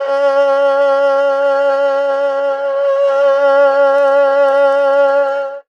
52-bi12-erhu-p-c#3.wav